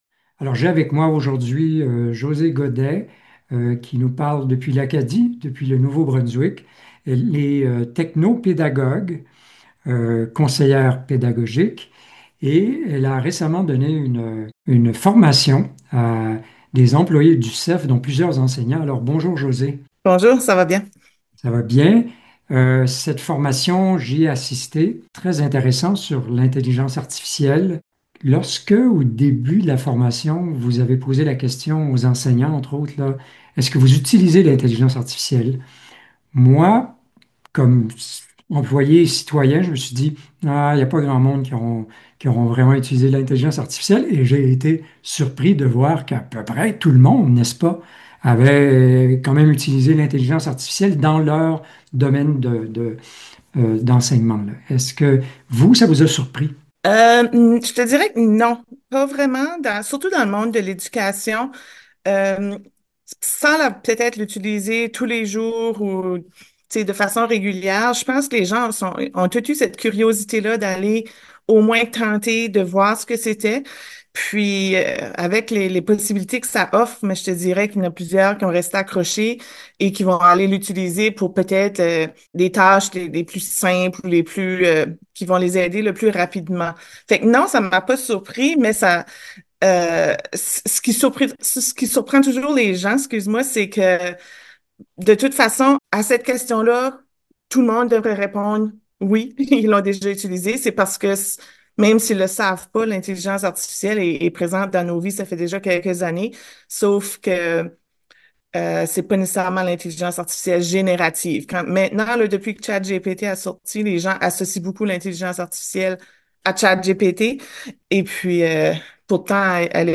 s'est entretenu avec elle afin de discuter de cette spectaculaire révolution qui fascine et qui fait peur à la fois.